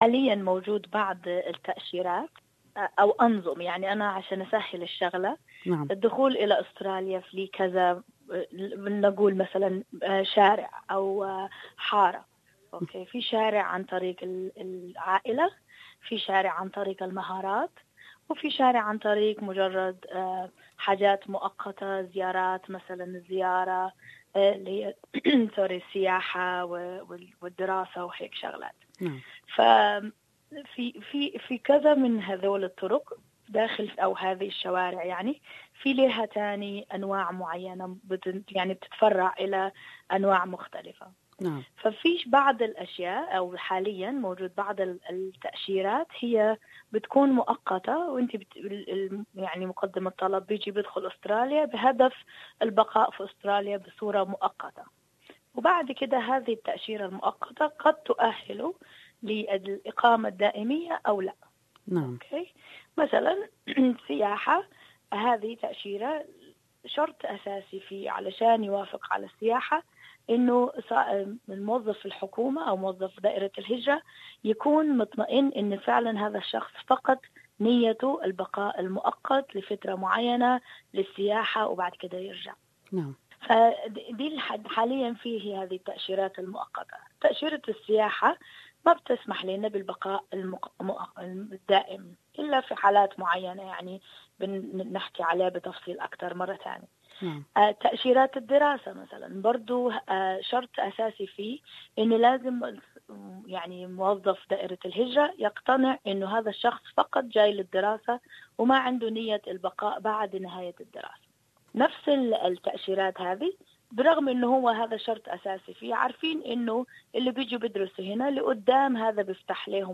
المزيد في هذه المقابلة.